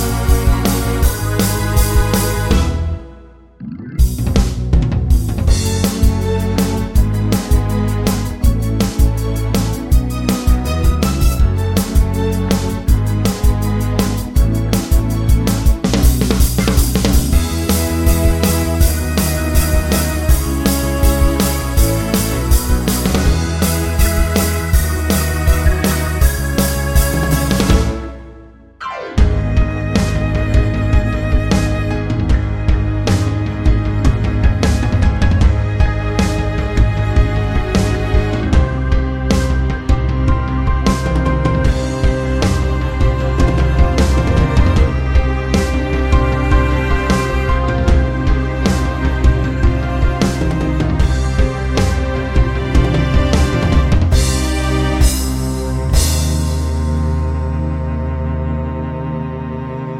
TV Length With No Backing Vocals Soundtracks 1:53 Buy £1.50